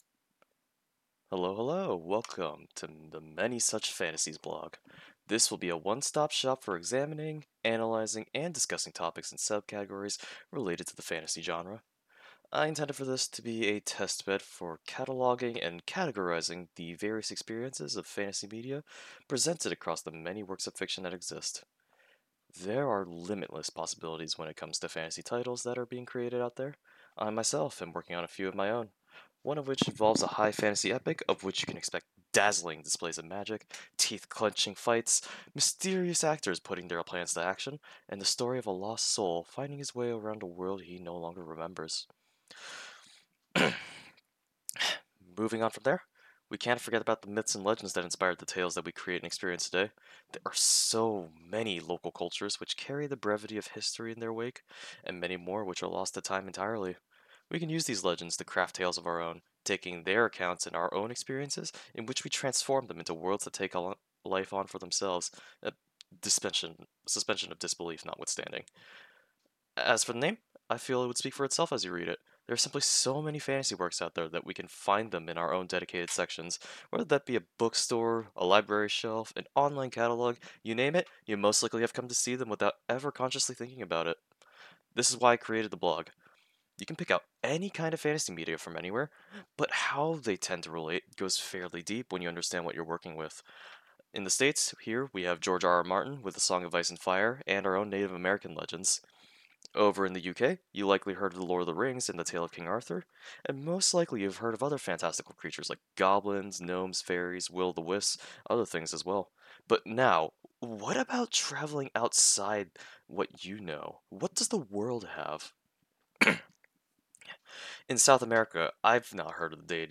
In explaining other mythologies and tales around the world, I may have mispronounced a few terms.